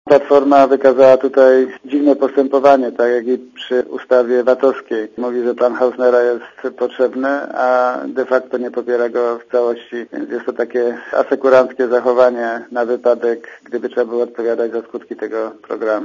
Posłuchaj komentarza Marka Dyducha (62kB)